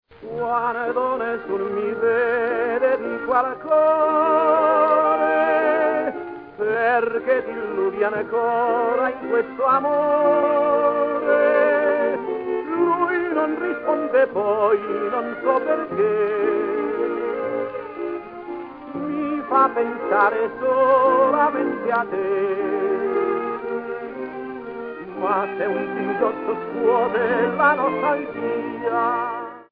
Ηχητική μπάντα παράστασης
sound track 01, διάρκεια 29", τραγούδι - μουσική